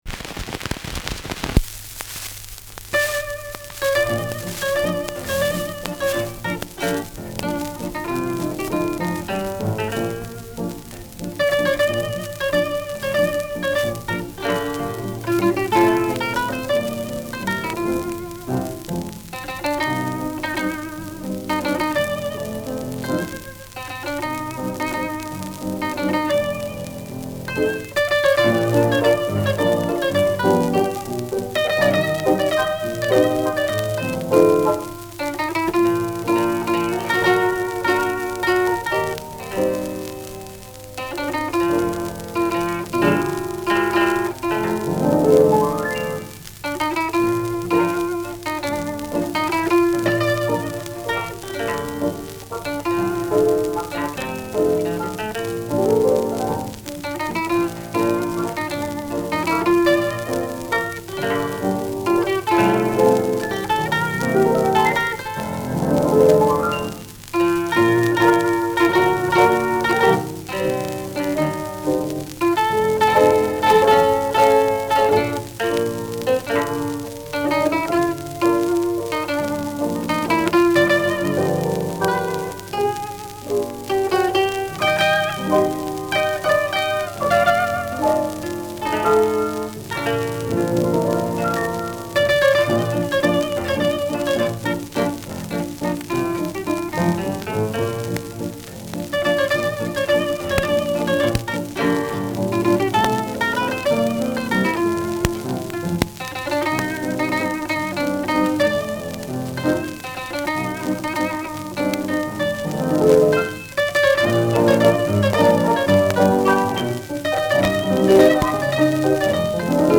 Schellackplatte
[Berlin] (Aufnahmeort)